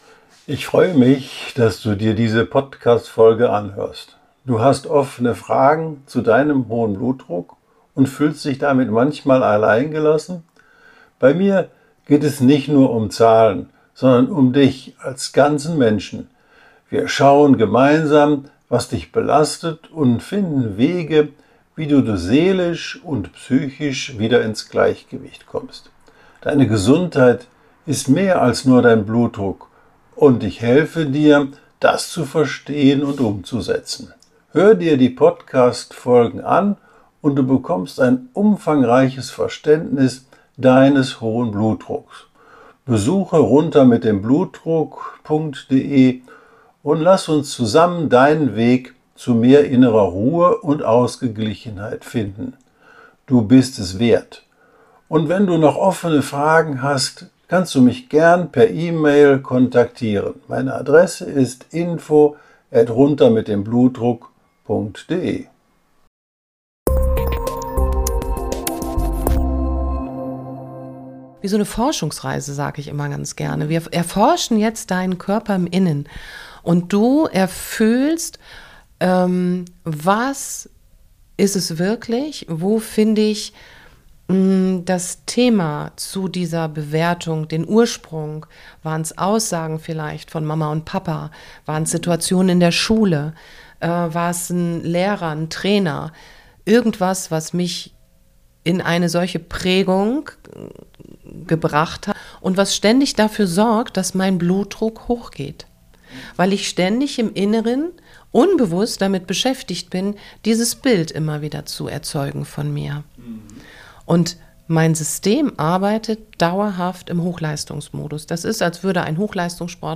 Er bat mich, aus meiner professionellen Sicht etwas zu entstehendem Blutdruck zu sagen. Es war ein sehr schönes, lockeres und angenehmes Gespräch mit viel Lachen, Herz